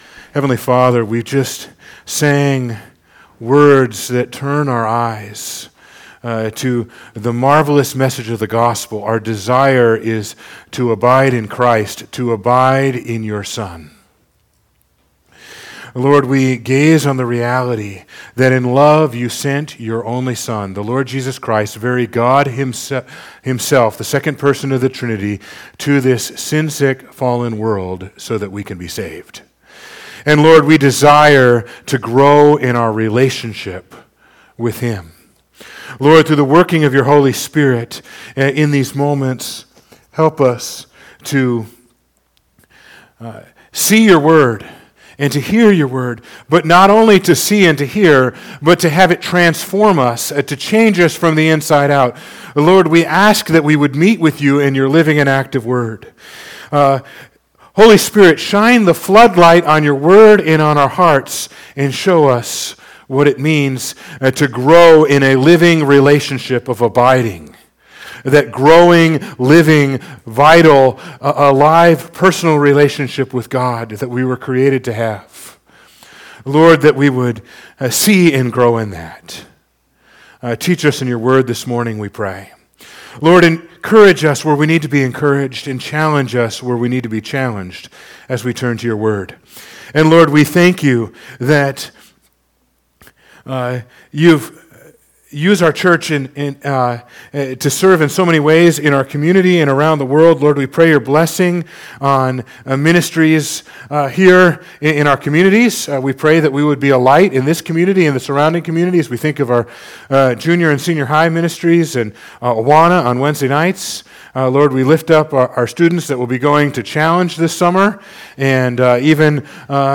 March 22, 2026 - Arthur Evangelical Free Church